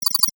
NOTIFICATION_Digital_06_mono.wav